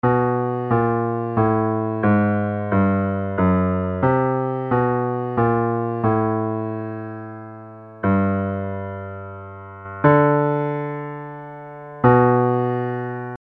Key written in: D Major
Each recording below is single part only.
a piano